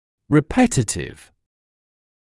[rɪ’petətɪv][ри’пэтэтив]повторяющийся